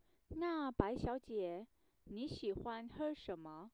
Sorry about that.